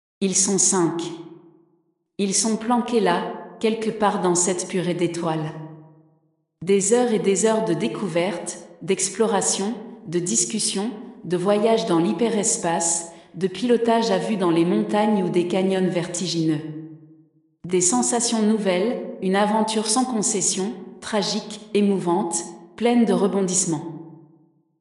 ARCHE-DU-CAPTAIN-BLOOD-L-Voix.mp3